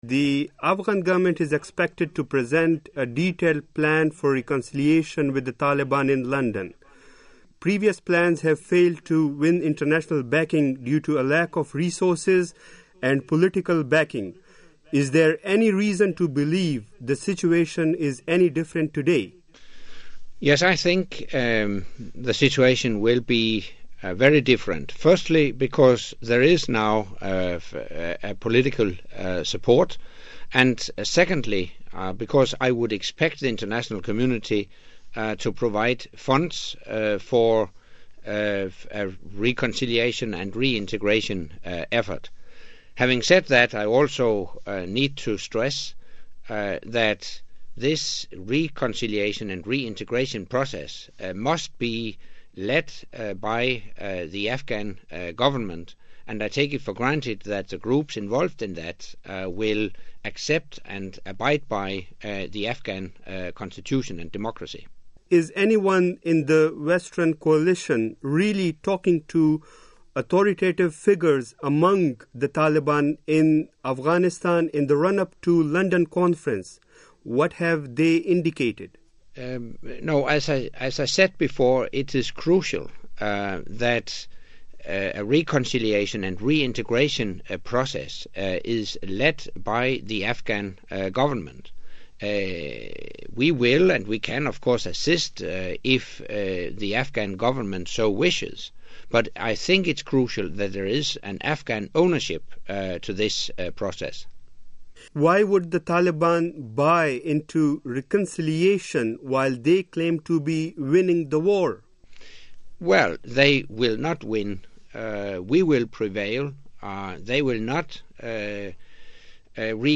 Interview With NATO Secretary-General Anders Fogh Rasmussen